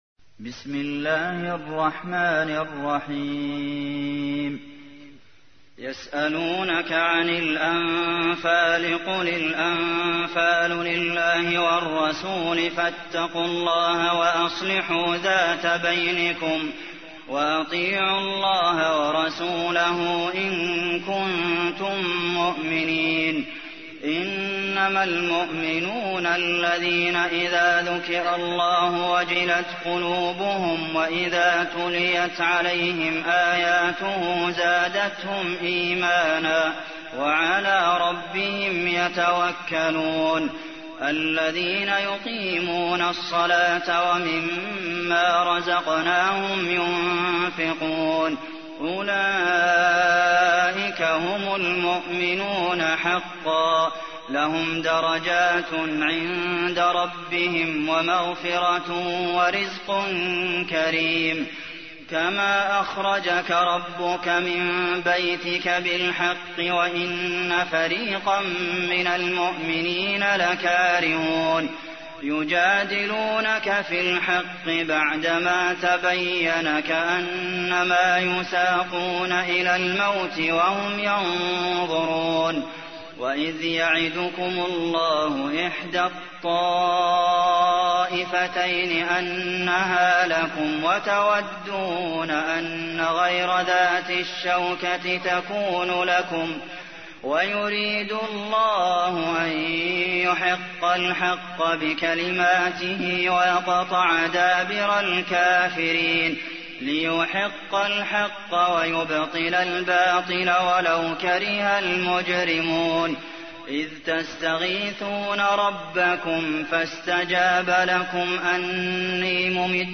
تحميل : 8. سورة الأنفال / القارئ عبد المحسن قاسم / القرآن الكريم / موقع يا حسين